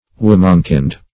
Womankind \Wom"an*kind`\, n.